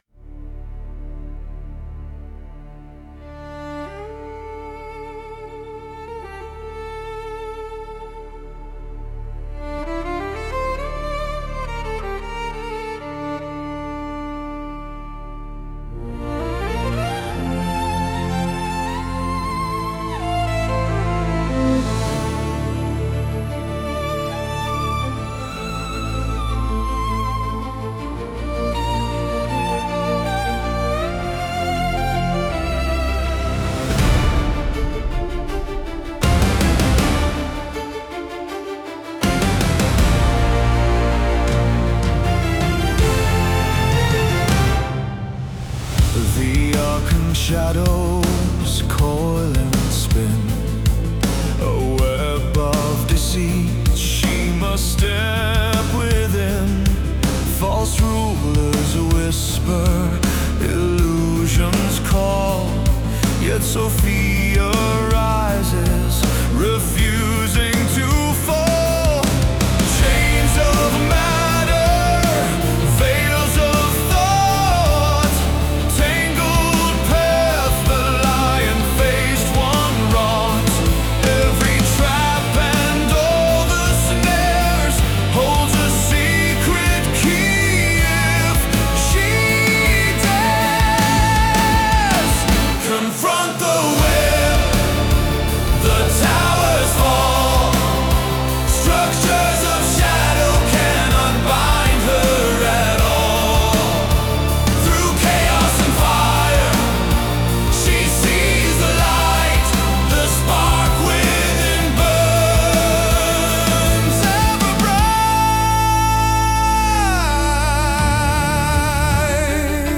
Full album with 8 Songs produced in 432Hz